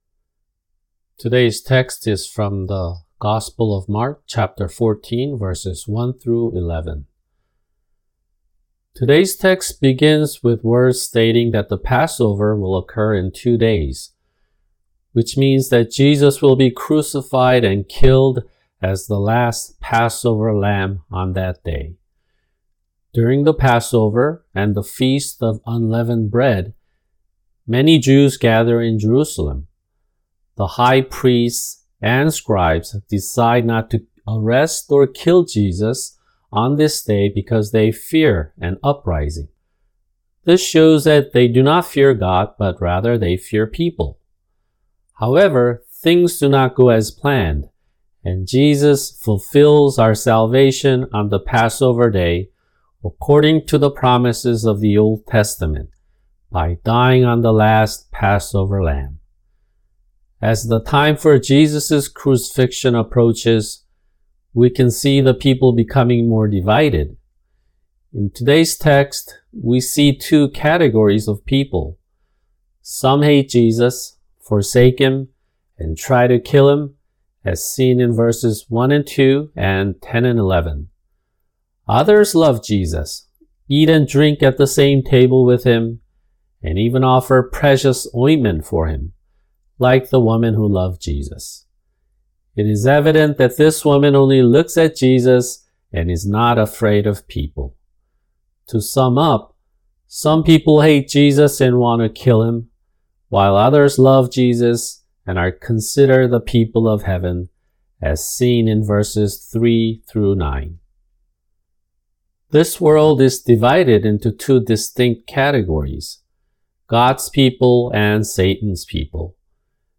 [주일 설교] 마가복음(63) 14:1-11